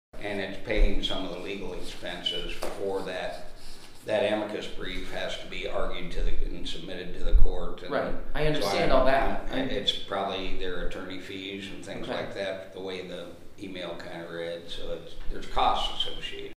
Supervisor Steve Green….